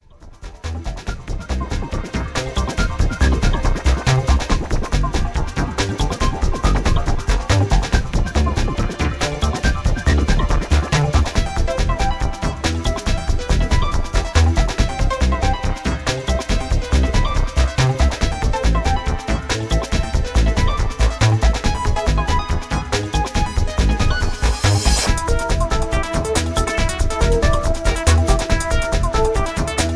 Electro Ambient with feel of tension